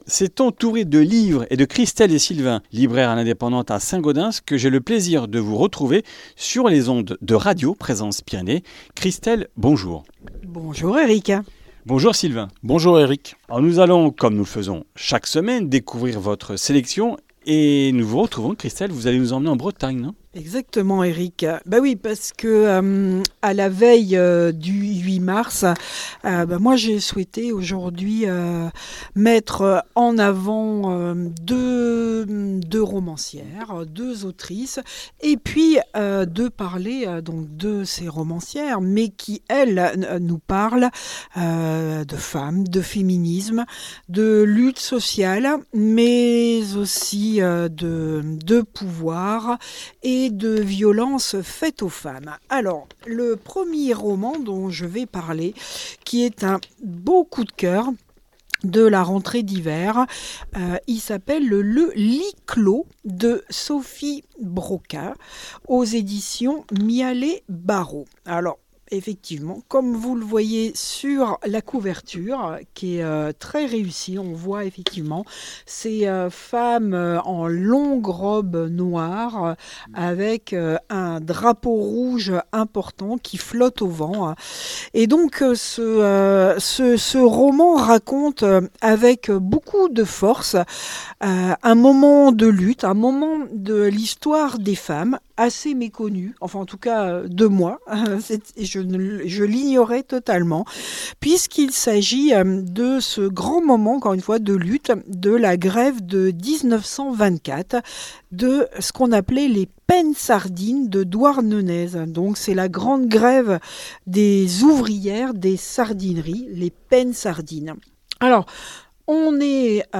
Comminges Interviews du 07 mars